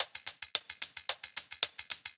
Index of /musicradar/ultimate-hihat-samples/110bpm
UHH_ElectroHatD_110-04.wav